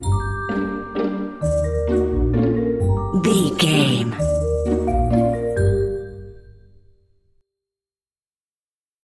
Uplifting
Ionian/Major
Slow
flute
oboe
strings
cello
double bass
percussion
violin
sleigh bells
silly
goofy
comical
cheerful
perky
Light hearted
quirky